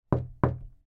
알림음 8_감사합니다1-여자.mp3